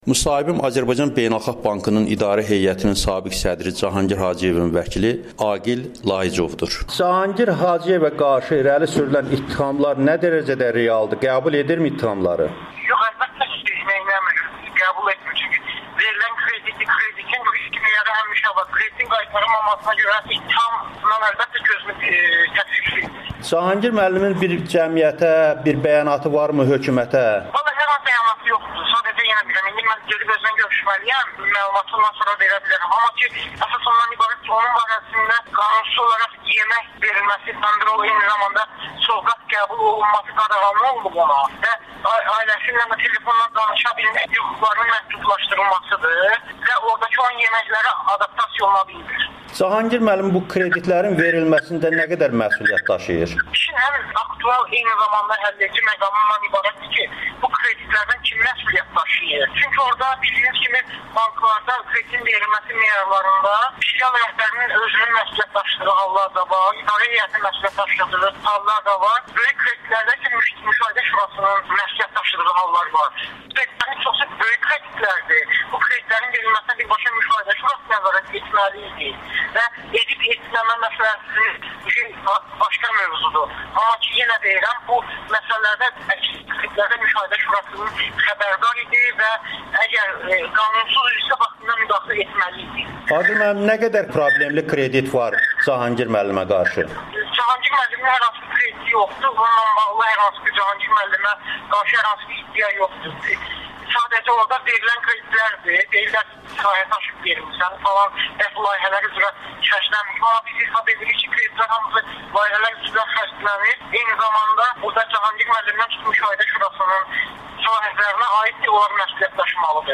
qanunsuz idisə vaxtında müdaxilə etməli idi [Audio-Müsahibə]